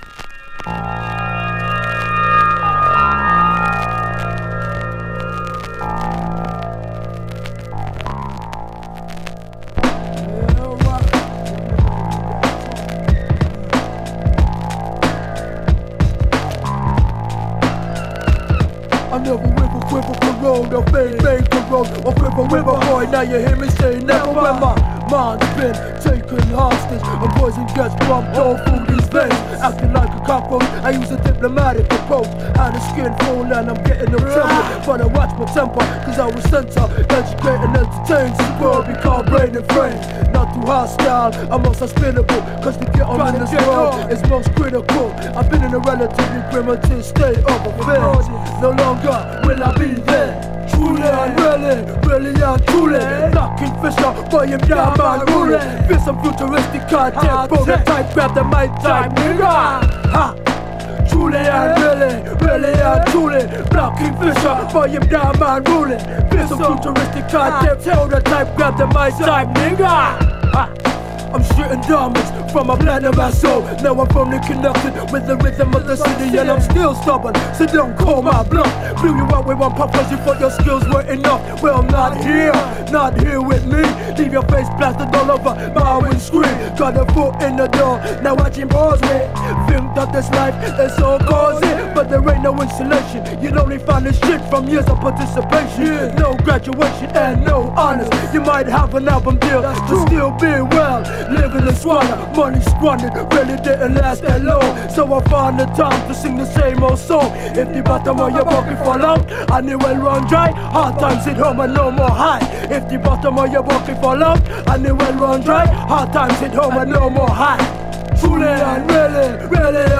※チリノイズあり